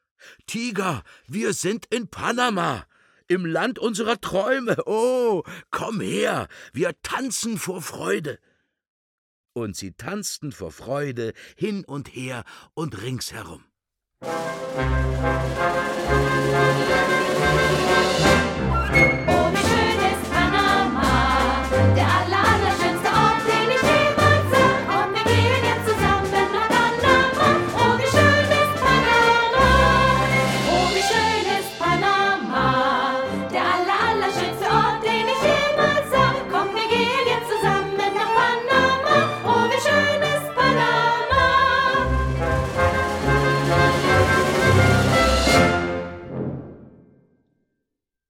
Dietmar Bär (Sprecher)